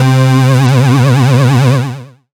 37ad01syn-c.wav